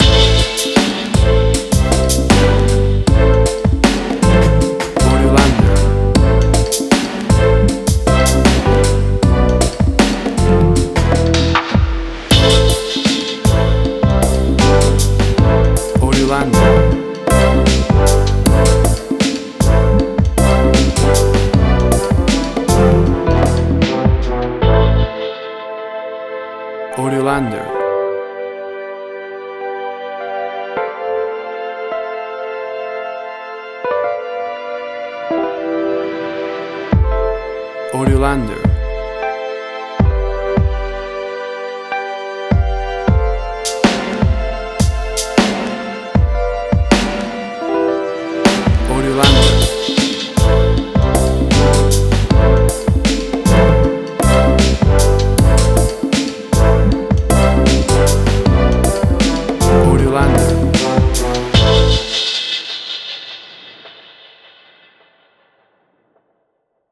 Tempo (BPM): 78